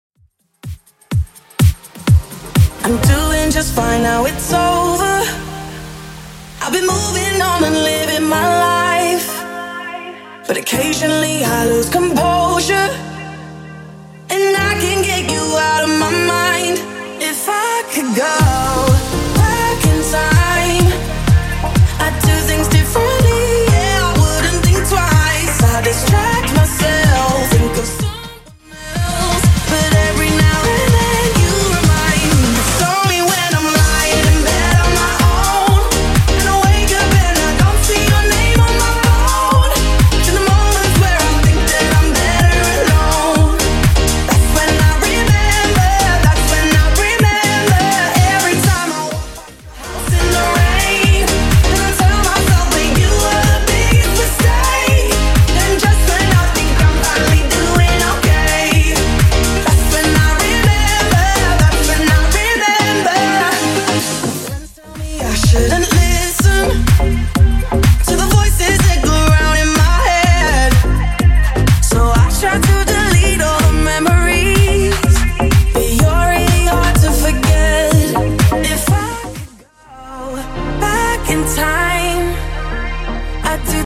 BPM: 125 Time